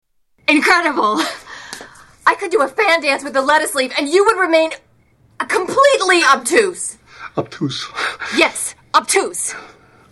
Tags: Movie Musical Hairspray Hairspray movie clips John Travolta